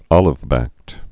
(ŏlĭv-băkt)